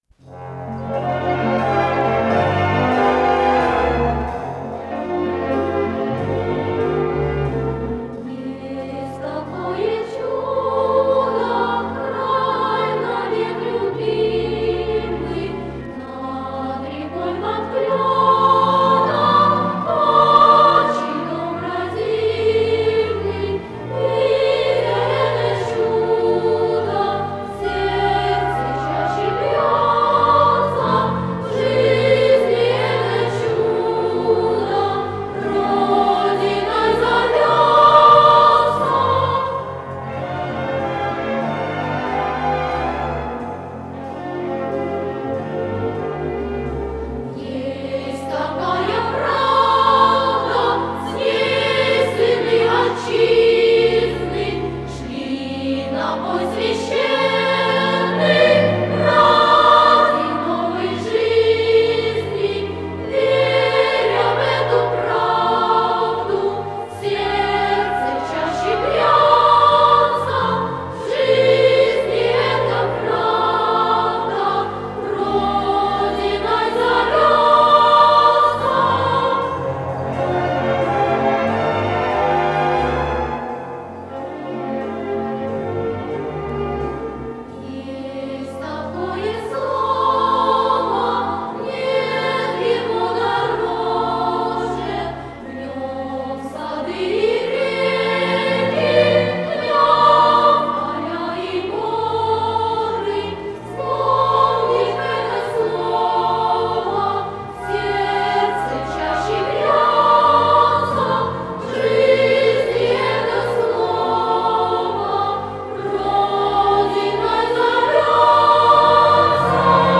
с профессиональной магнитной ленты
АккомпаниментСимфонический оркестр
ВариантДубль моно